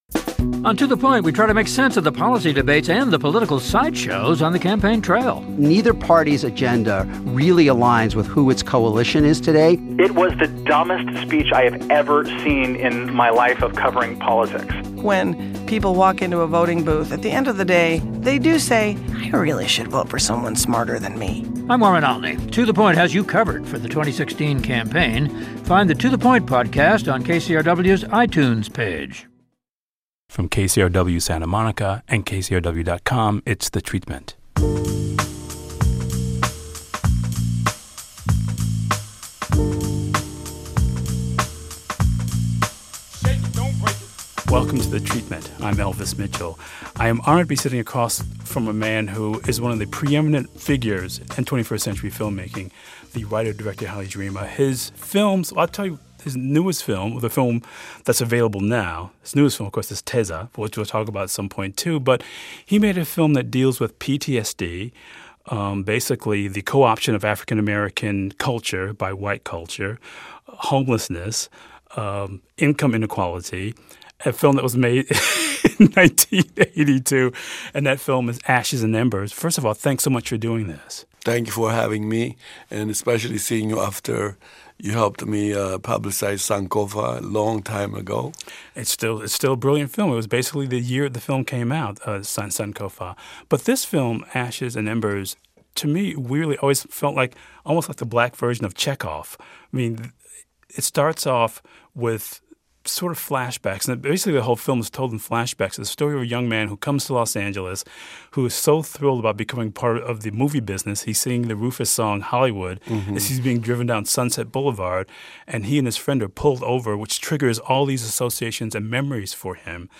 Filmmaker Haile Gerima joins Elvis Mitchell to discuss African American displacement in white society in Ashes and Embers.